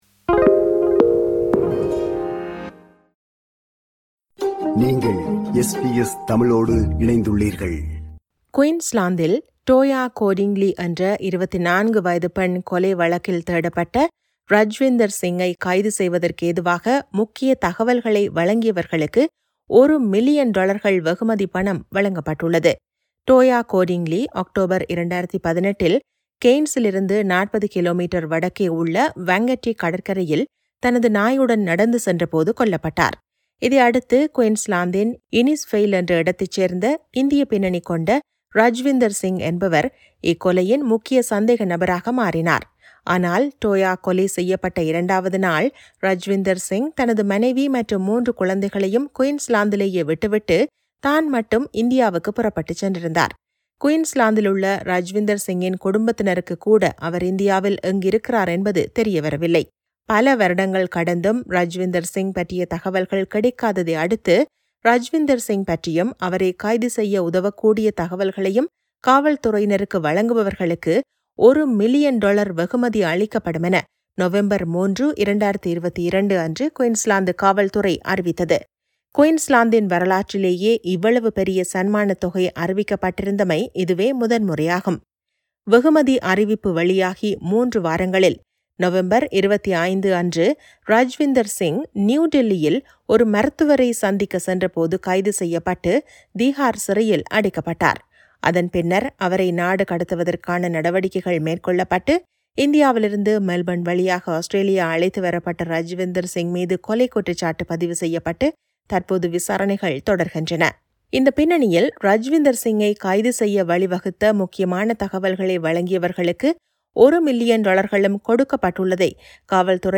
இதுகுறித்த செய்தியைத் தருகிறார்